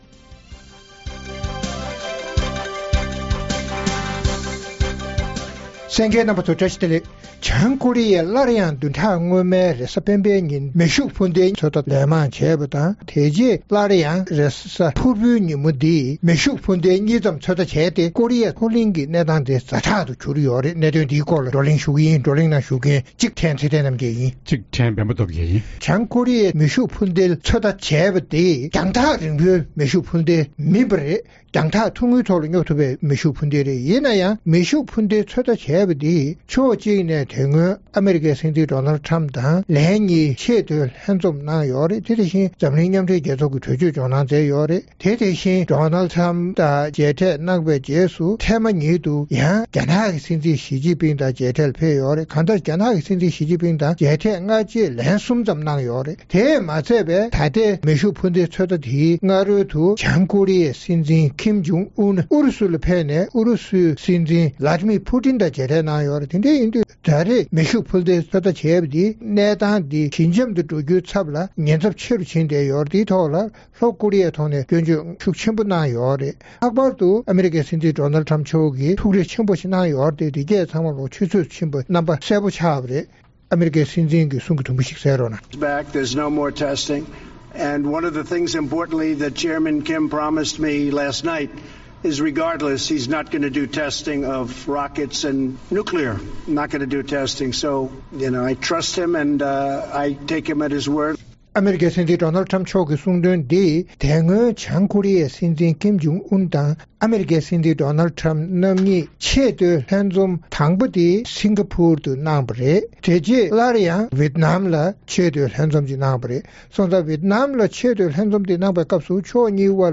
བྱང་ཀོ་རི་ཡས་བདུན་ཕྲག་སྔོན་མར་སླར་ཡང་མེ་ཤུགས་འཕུར་མདེལ་ཚོད་བལྟ་ལན་མང་བྱས་ཤིང་། ཨ་མེ་རི་ཁས་དཔལ་འབྱོར་བཀག་སྡོམ་དམ་དྲག་ཆེ་རུ་བཏང་བ་སོགས་ཀྱི་གནད་དོན་སྐོར་རྩོམ་སྒྲིག་འགན་འཛིན་རྣམ་པས་བགྲོ་གླེང་གནང་བ་ཞིག་གསན་རོགས་གནང་།